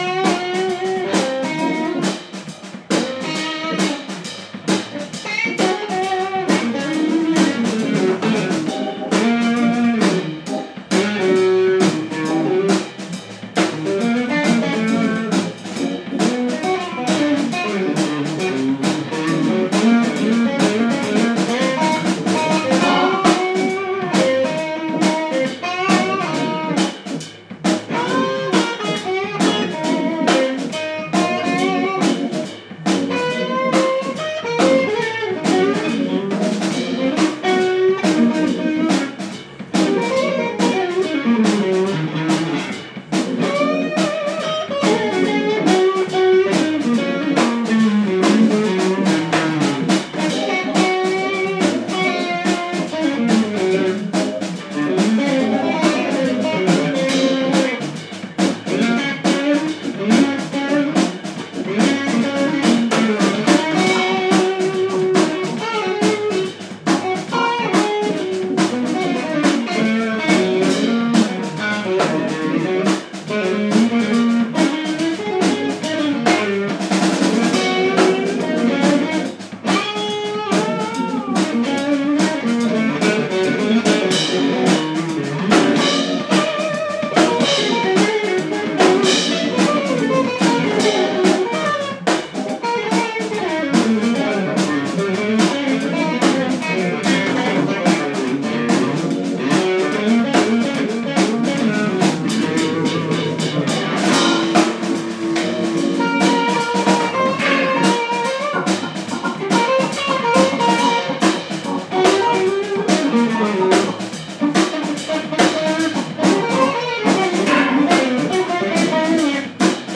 Disquisizioni sonore sul concetto alternativo di armonia.
organ / keyboards
saxes
guitar
drums / synth